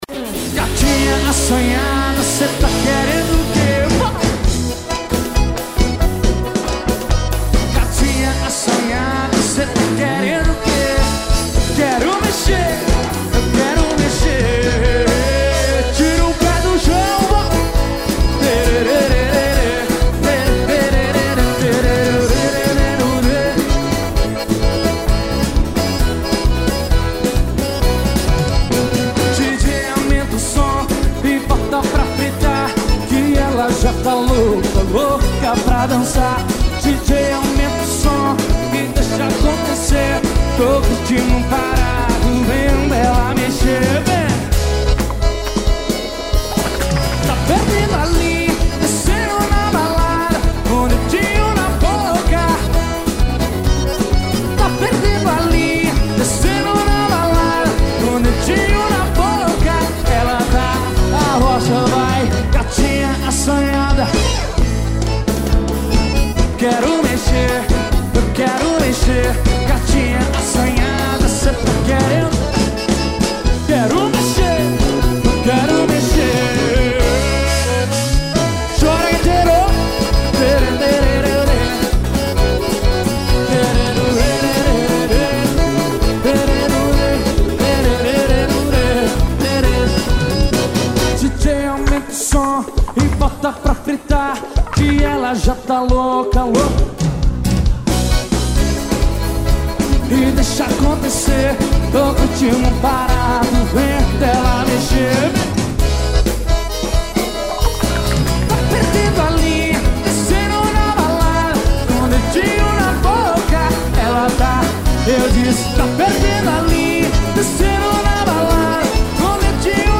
Composição: AO VIVO.